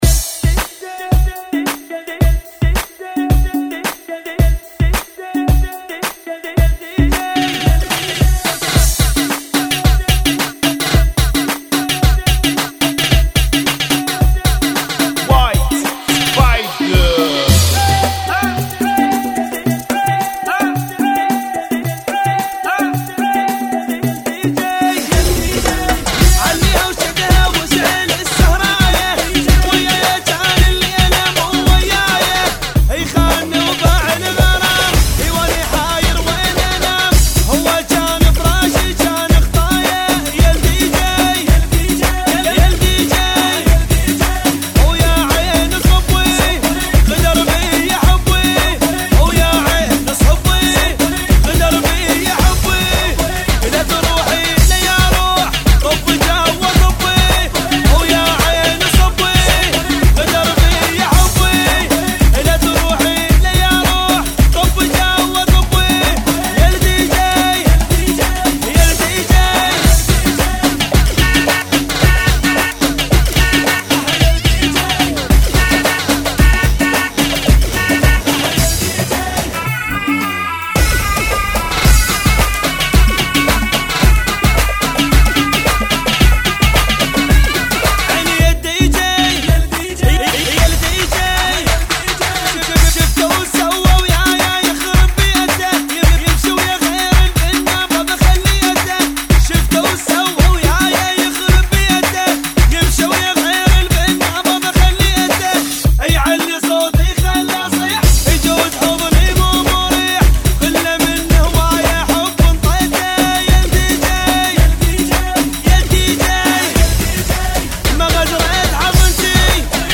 Funky [ 110 Bpm ]